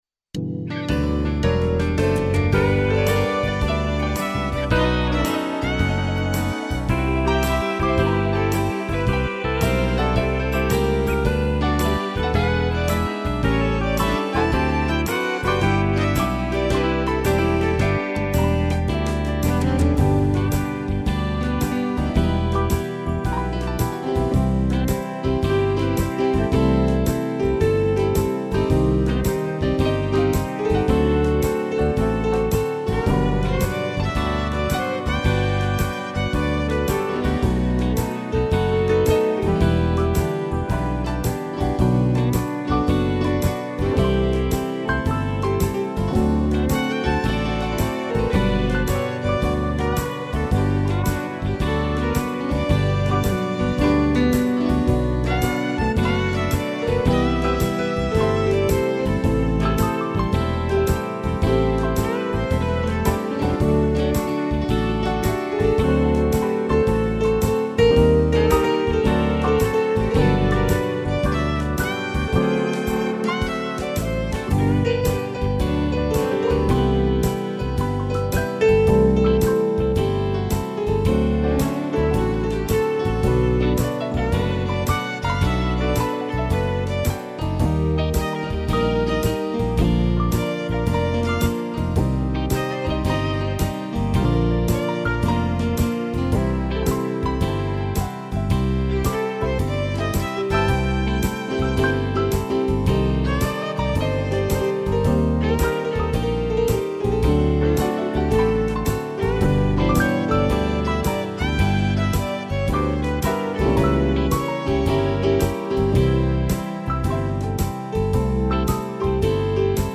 piano, sax e violino
instrumental